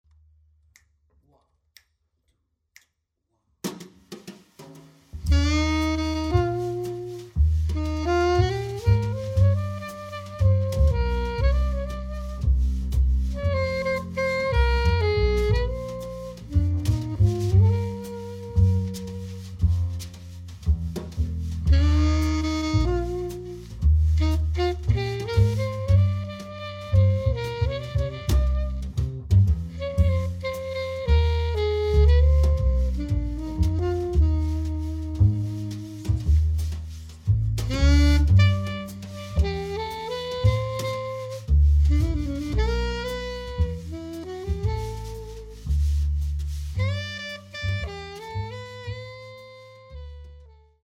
sax
bass
drums